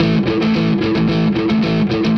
AM_HeroGuitar_110-A02.wav